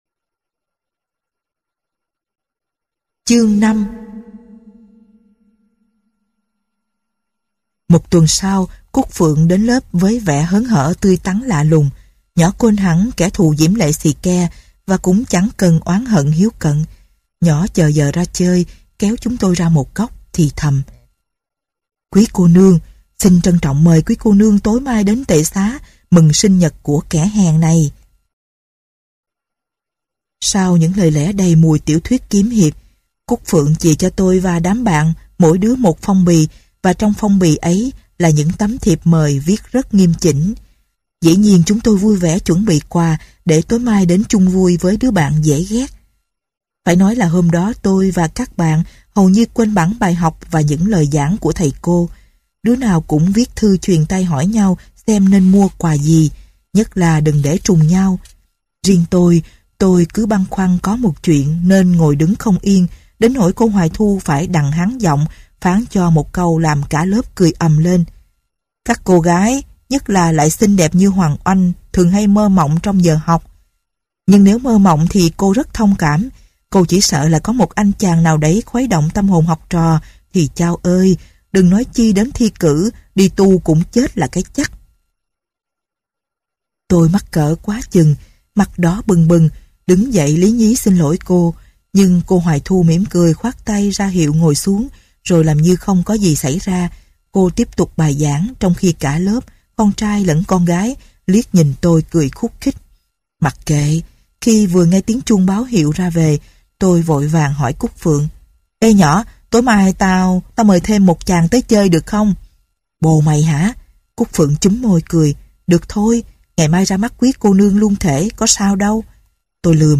Sách nói Nhật Ký Buồn Cho Hải Âu - Nguyễn Trí Công - Sách Nói Online Hay